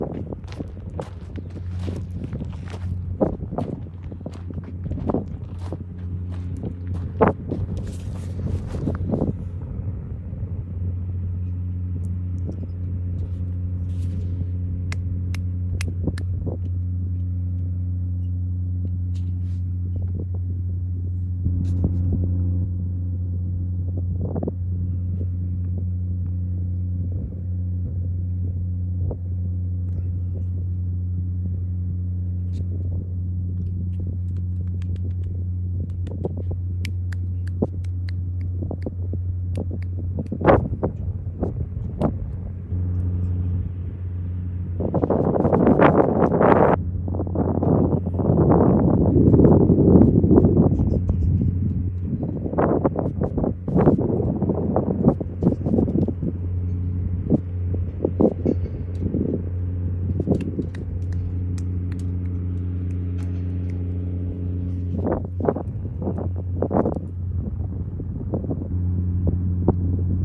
Fox encounter near work sound effects free download